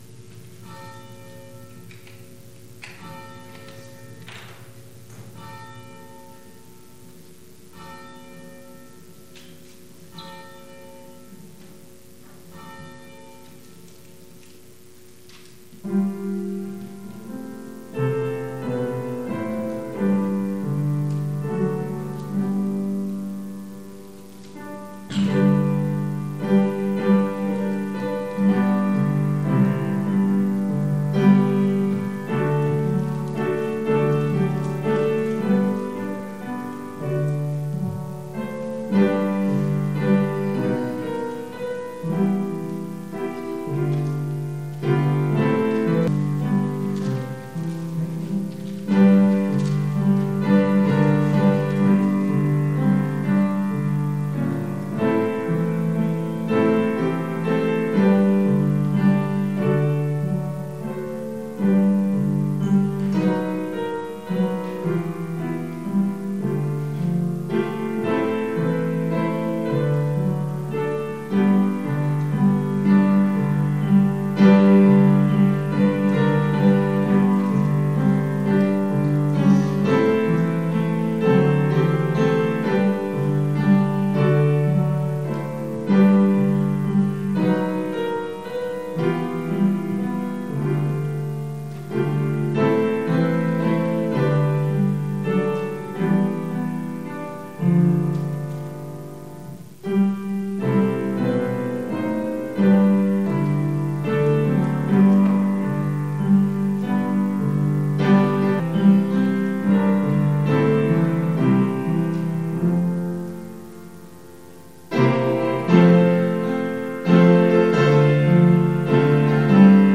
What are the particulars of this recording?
Due to technical issues this week’s service is audio only.